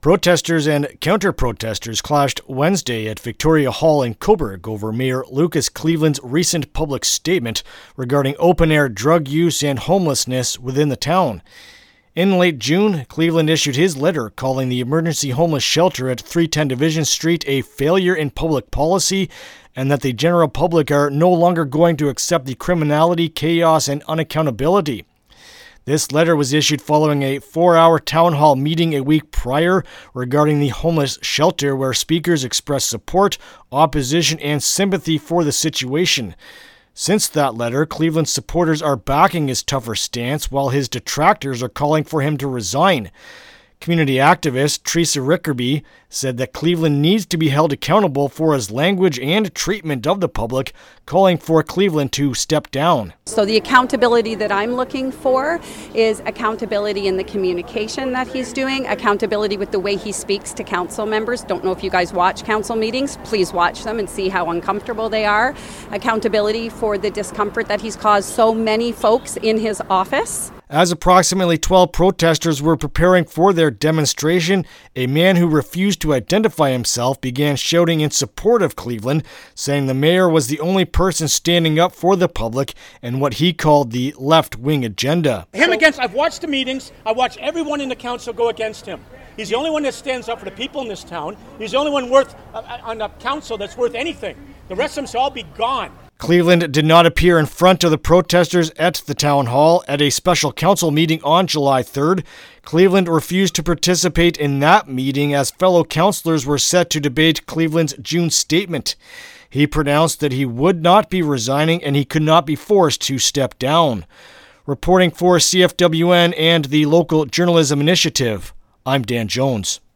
Cobourg-Homeless-protest-Report-LJI.mp3